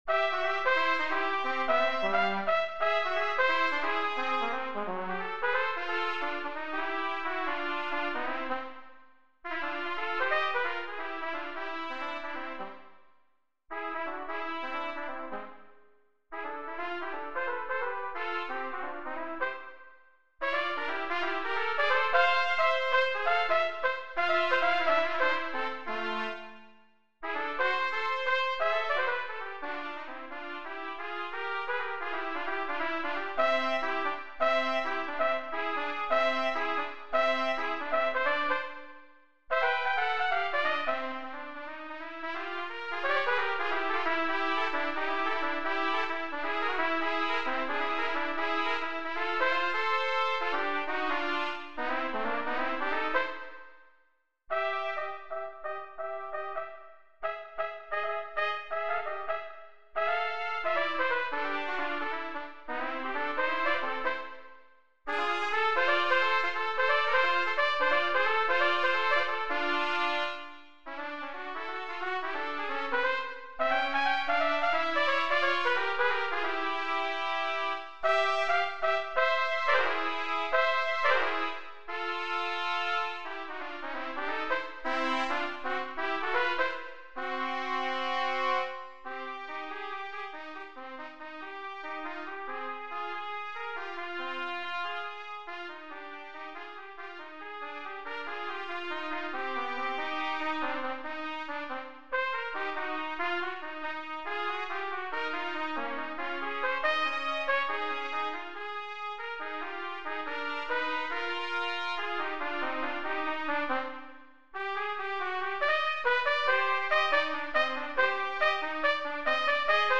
Gattung: Für 2 Trompeten
Besetzung: Instrumentalnoten für Trompete